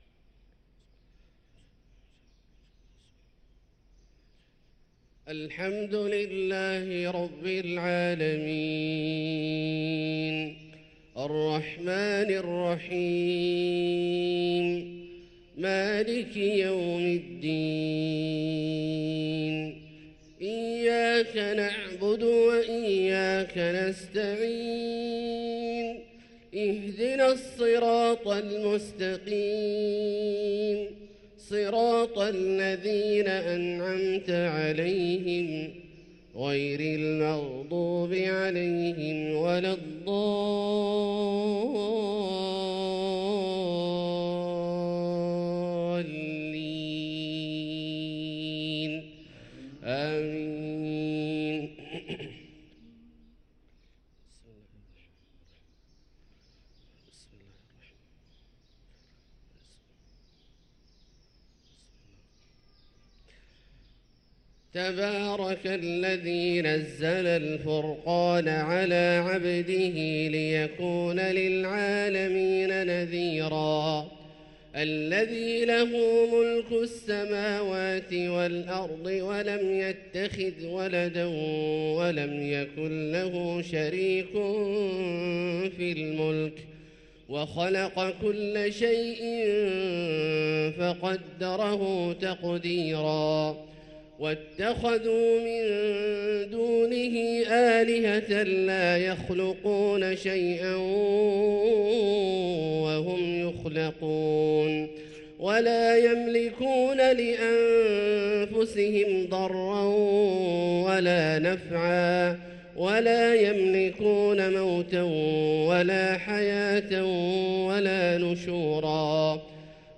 صلاة الفجر للقارئ عبدالله الجهني 14 جمادي الآخر 1445 هـ